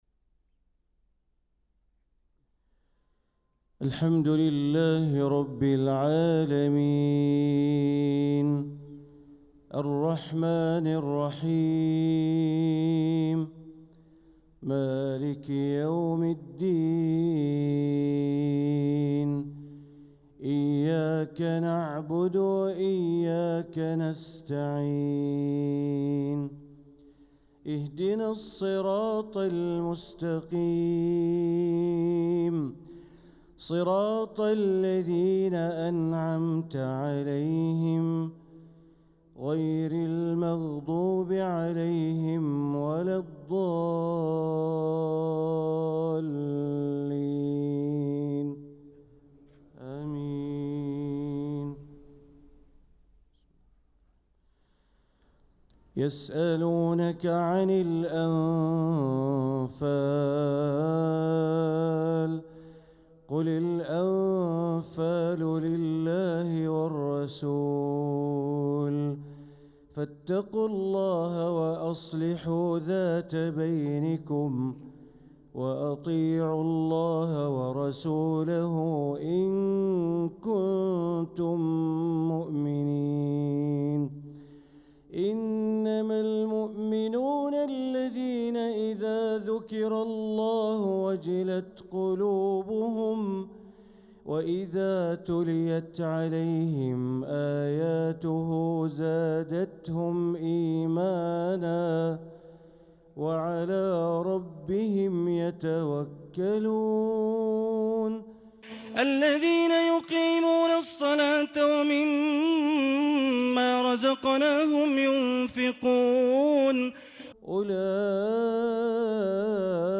صلاة الفجر للقارئ بندر بليلة 28 صفر 1446 هـ